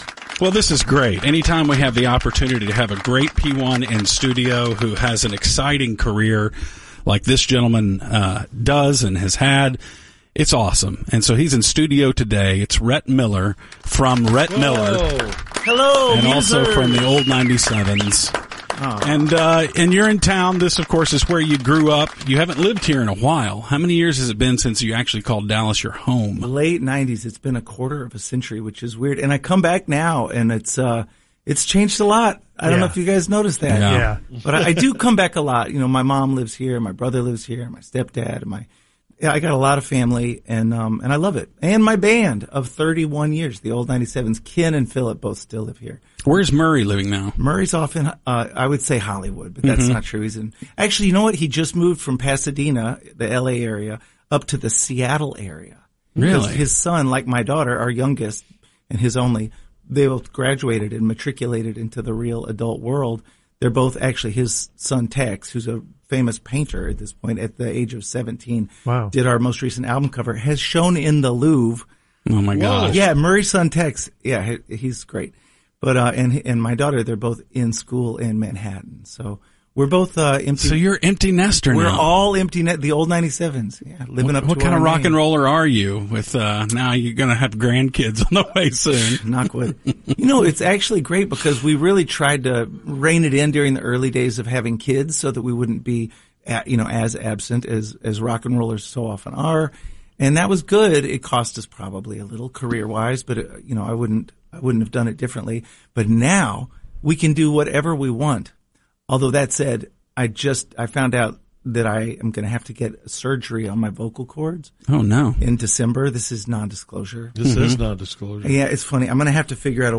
Rhett of the Old 97s is in studio.